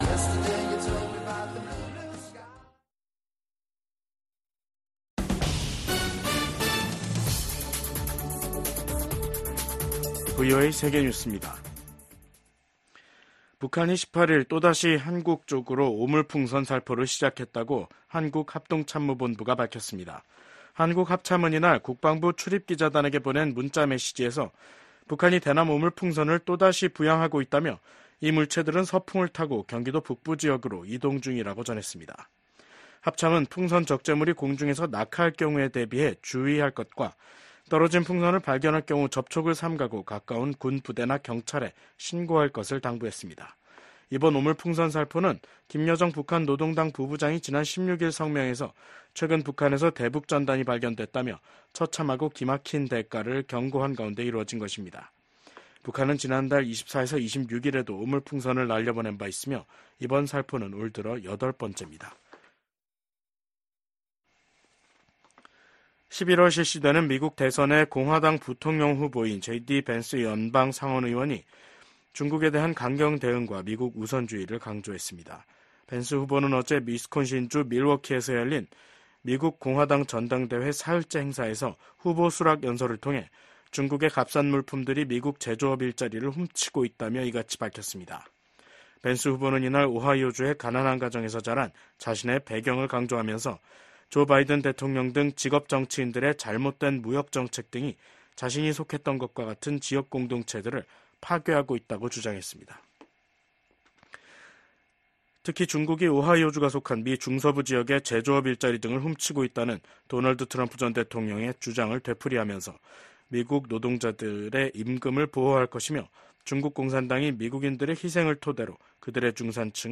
VOA 한국어 간판 뉴스 프로그램 '뉴스 투데이', 2024년 7월 18일 3부 방송입니다. 미 중앙정보국(CIA) 출신의 대북 전문가가 미 연방검찰에 기소됐습니다. 북한이 신종 코로나바이러스 감염증 사태가 마무리되면서 무역 봉쇄를 풀자 달러 환율이 고공행진을 지속하고 있습니다. 최근 북한을 방문한 유엔 식량농업기구 수장이 북한이 농업 발전과 식량 안보에서 큰 성과를 냈다고 주장했습니다.